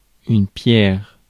Ääntäminen
France (Paris): IPA: [yn pjɛʁ]